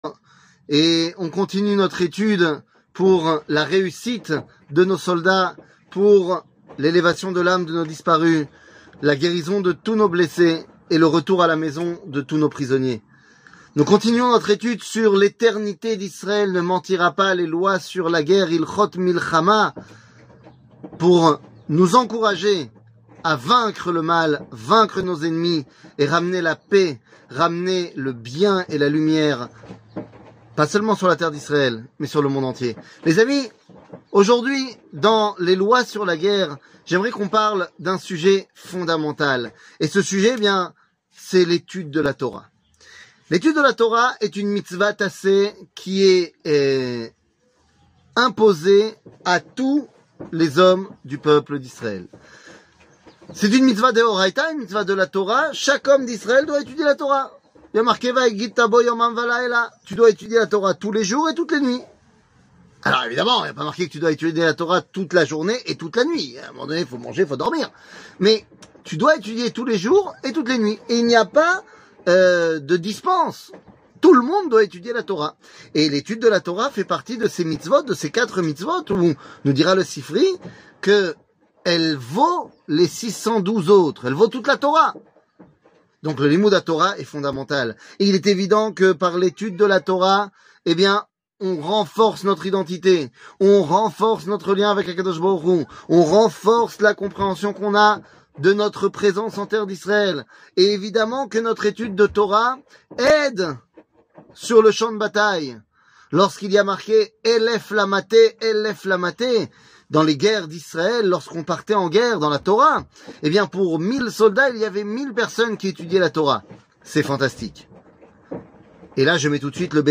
L eternite d Israel ne mentira pas ! 5 00:09:35 L eternite d Israel ne mentira pas ! 5 שיעור מ 12 אוקטובר 2023 09MIN הורדה בקובץ אודיו MP3 (8.77 Mo) הורדה בקובץ וידאו MP4 (17.54 Mo) TAGS : שיעורים קצרים